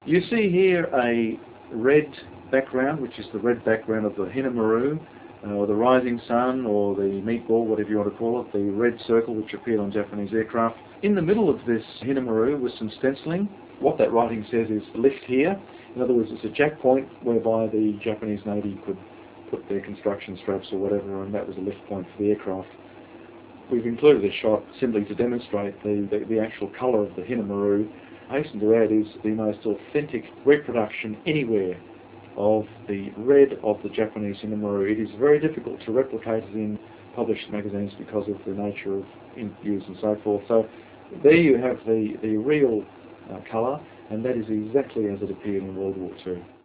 Pacific Ghosts - Audio Commentary
The sample audio below is compressed for streaming via 28.8 modem.